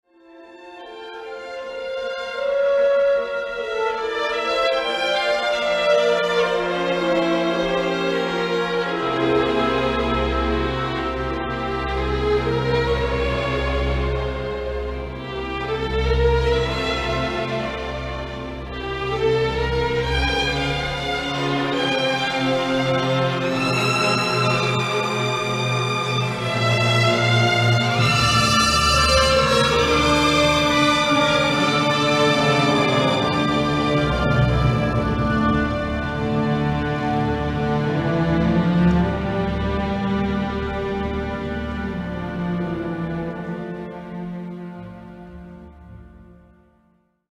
Jego wybór jest jednak trafny, a muzyka prowadzona jest z dużym wyczuciem i elegancją. Jednak kiedy trzeba – wykonawcy prowadzą muzykę w pełen rozmachu, wyrazisty sposób:
Główny punkt kulminacyjny niestety jest przesterowany, a zakończenie tej części jest zbyt pospieszne i po prostu urywa się, zamiast rozpłynąć się w ciszy.